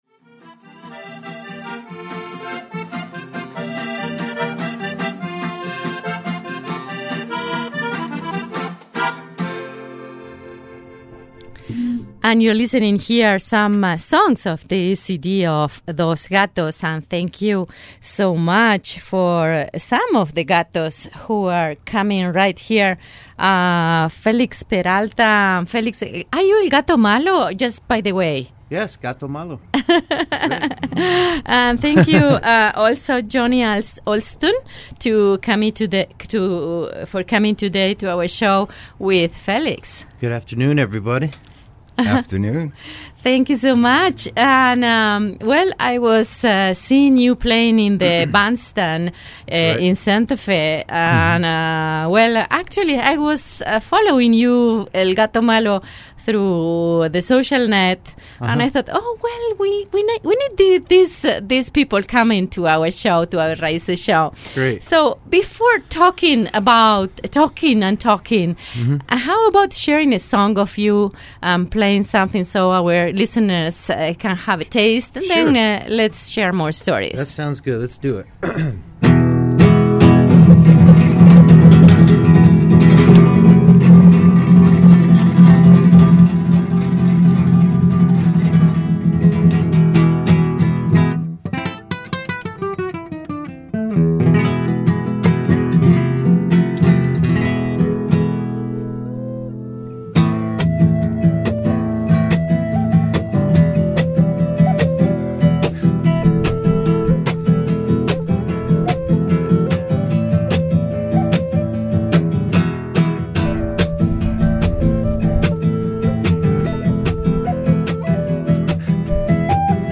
Be ready for “Raw” New Mexican music with live performance
Afro-Native American flutist